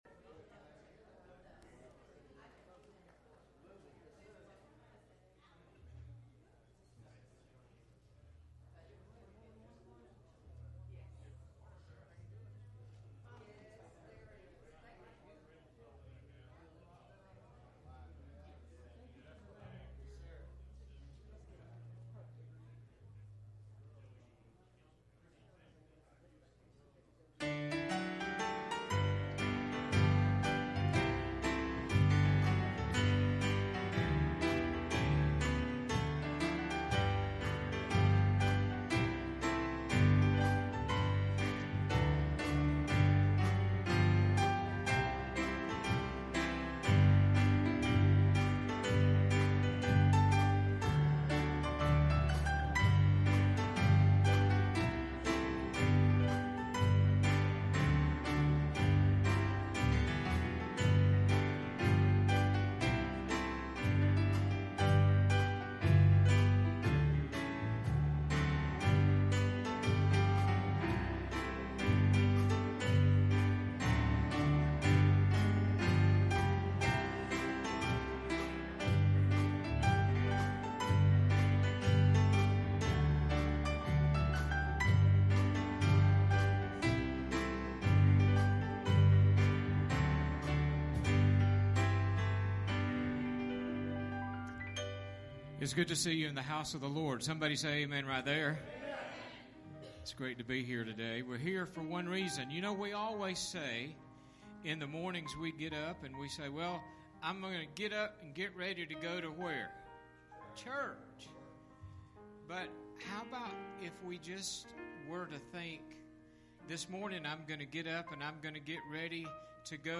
Series: Message
Service Type: Sunday Morning